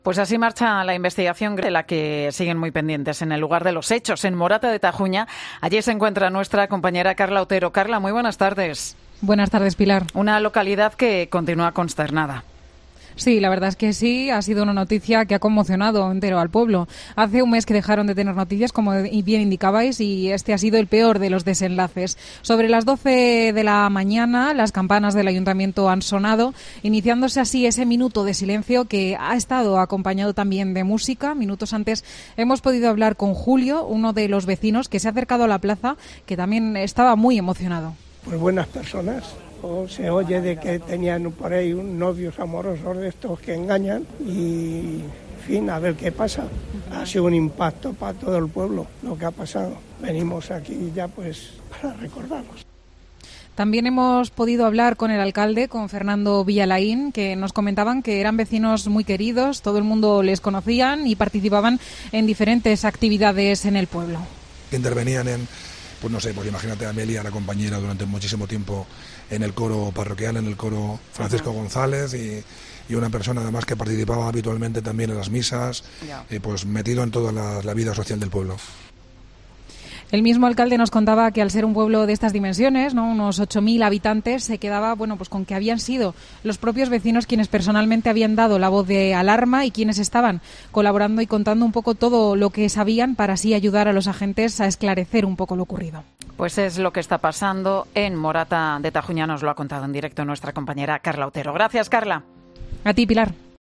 COPE testigo del homenaje de los vecinos de Morata a los tres hermanos encontrados muertos